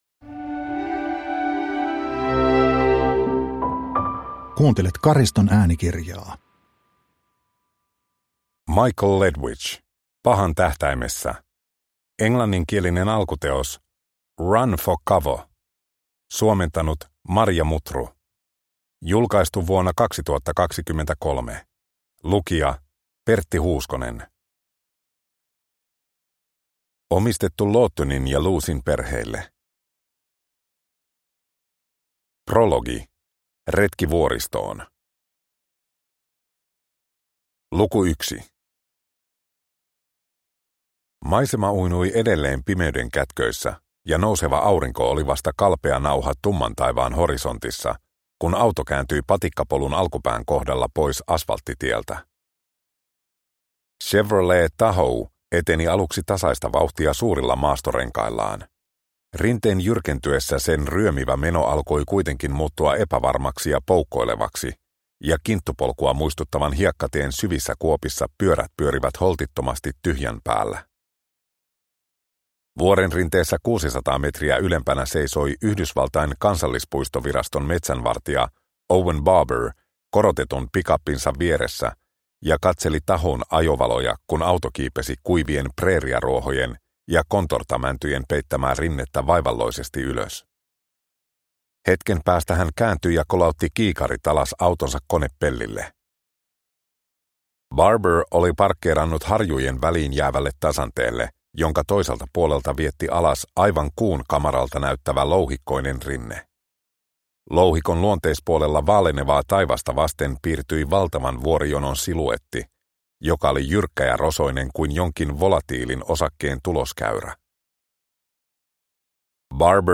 Pahan tähtäimessä – Ljudbok – Laddas ner
Produkttyp: Digitala böcker